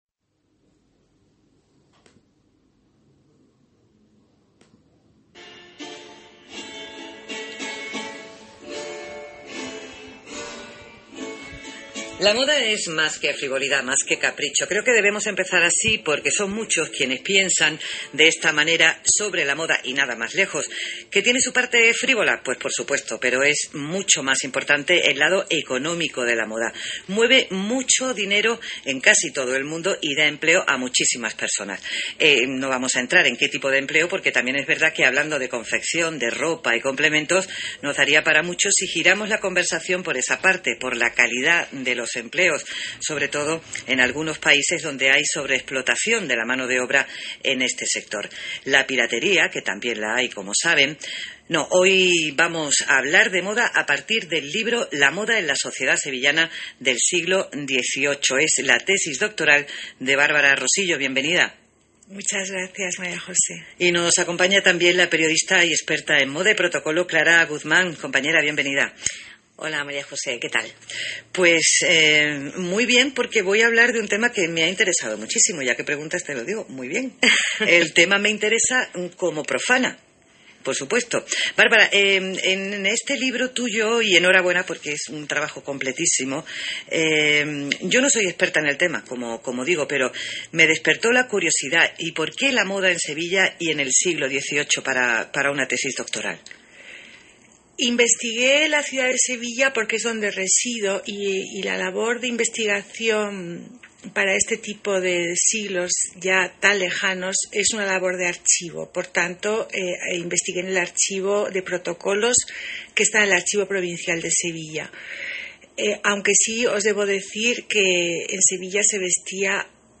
Entrevista en Radio Nacional Radio 5
entrevista-radio-nacional.mp3